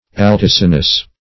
Altisonous \Al*tis"o*nous\ (-n[u^]s), a.